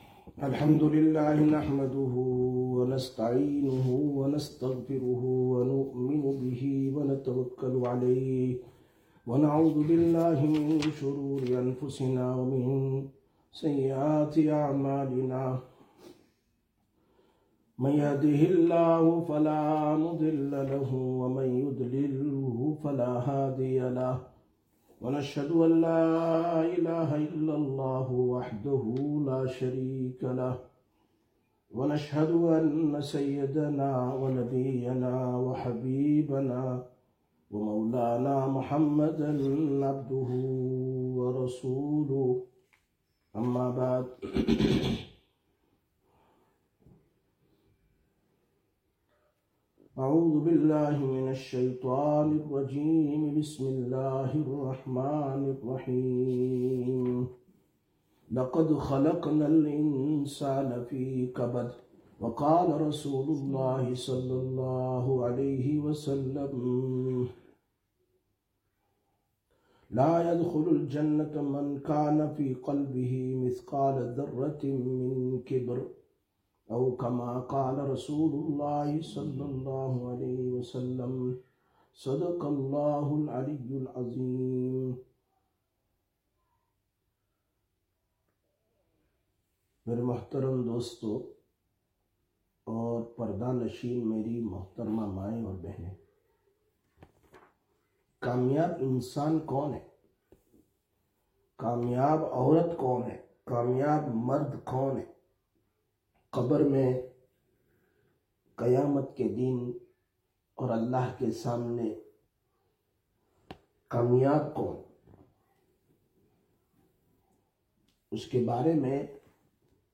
23/07/2025 Sisters Bayan, Masjid Quba